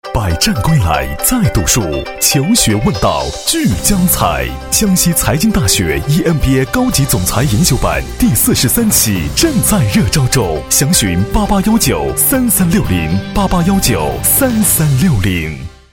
职业配音员 全职配音员
男声 宣传片-总裁43期-教育培训 成熟有力 沉稳|娓娓道来|积极向上